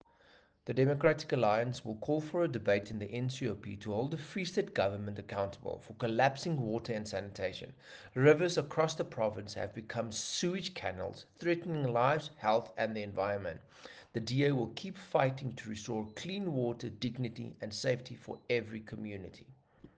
Afrikaans soundbites by Dr Igor Scheurkogel MP and